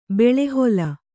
♪ beḷhola